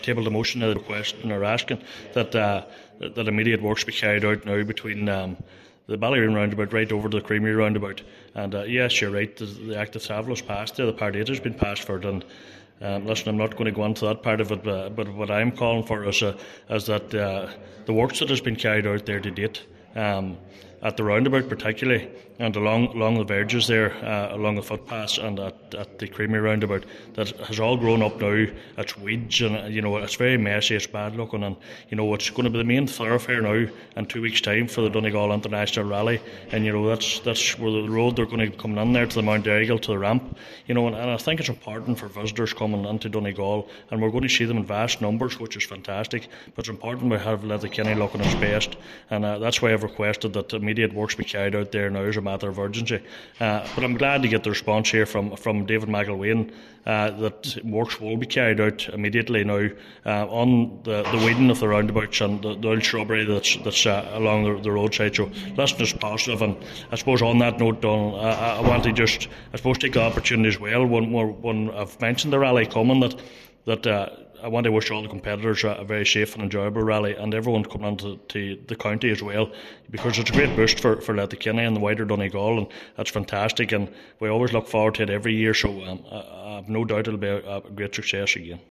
Cllr Kelly says it’s important that this work is done before the event gets underway: